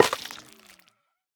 Minecraft Version Minecraft Version 1.21.5 Latest Release | Latest Snapshot 1.21.5 / assets / minecraft / sounds / block / sculk_catalyst / break9.ogg Compare With Compare With Latest Release | Latest Snapshot